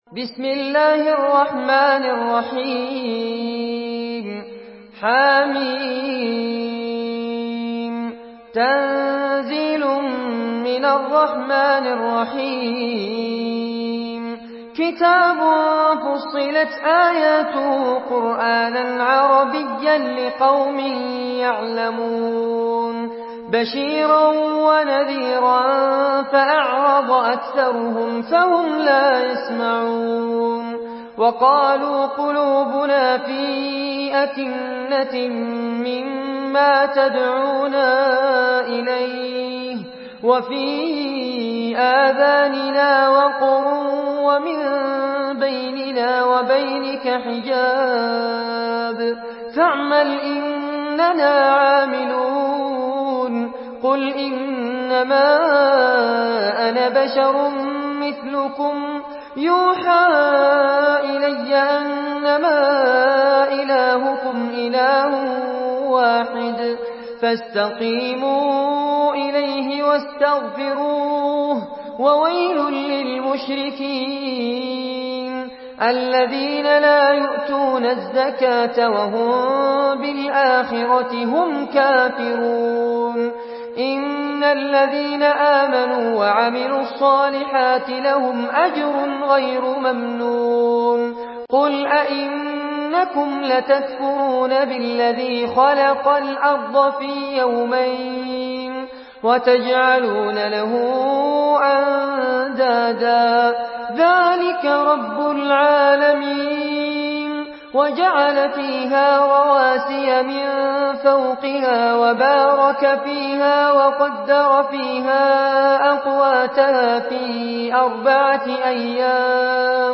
Surah فصلت MP3 by فارس عباد in حفص عن عاصم narration.